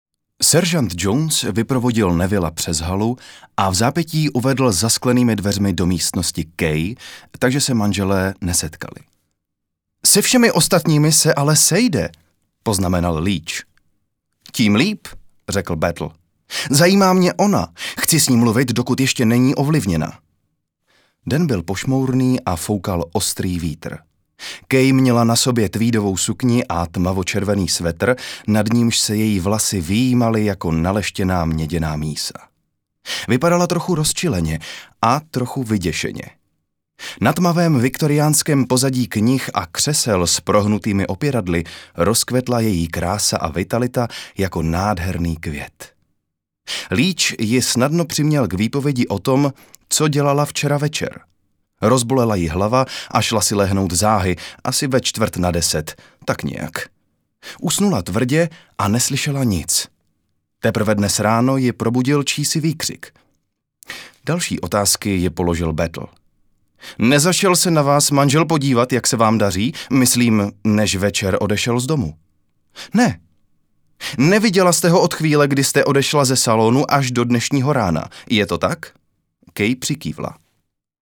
ukázka audio kniha:
audio-kniha.mp3